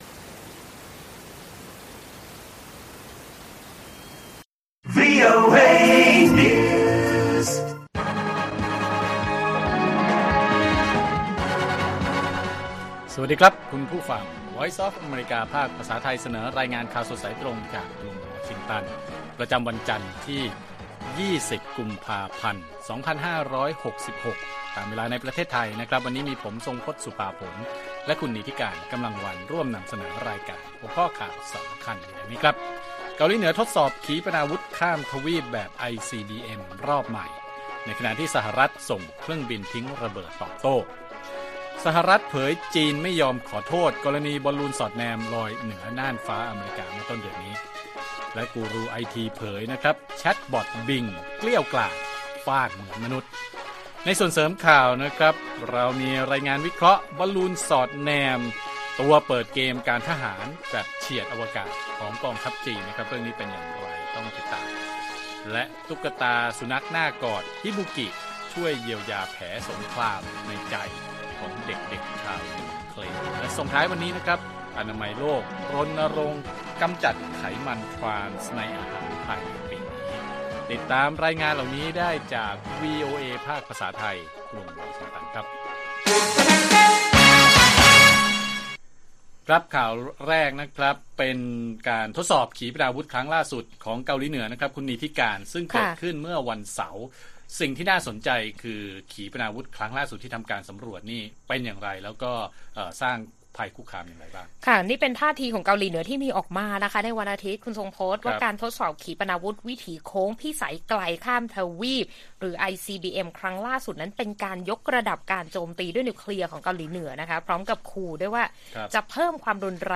ข่าวสดสายตรงจากวีโอเอไทย 6:30 – 7:00 น. วันที่ 19 ก.พ. 2566